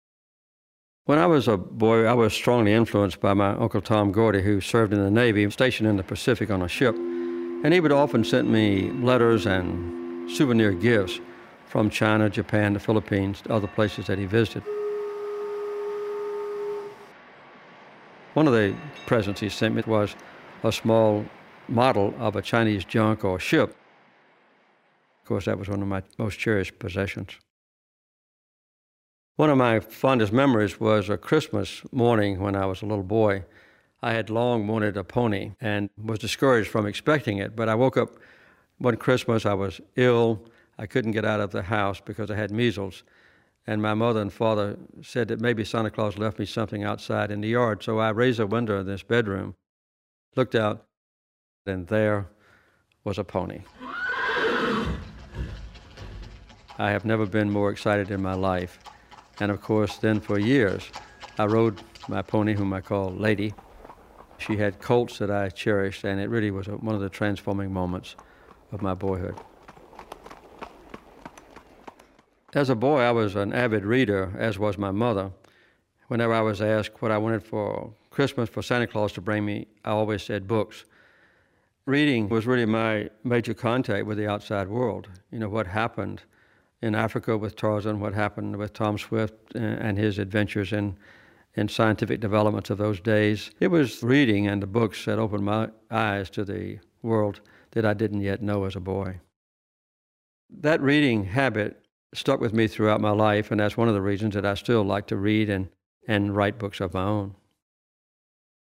Jimmy Carter recalls his most cherished possessions, including one special Christmas gift.